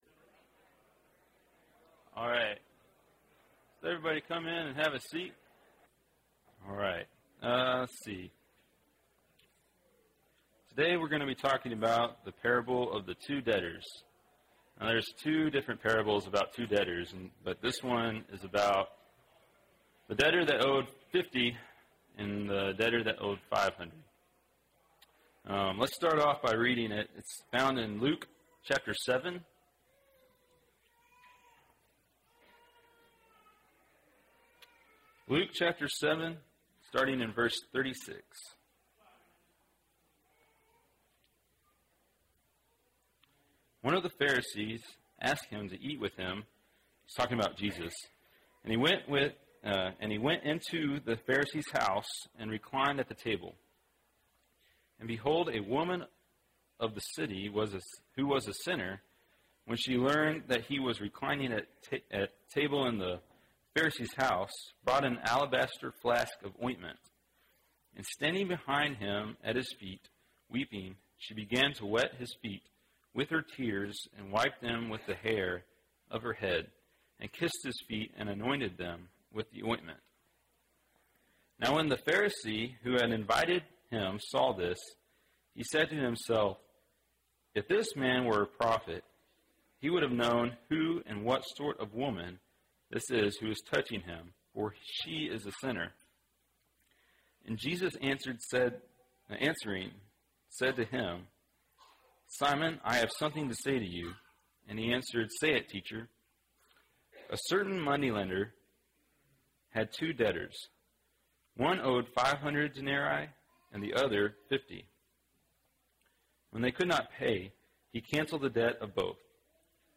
Wednesday PM Bible Class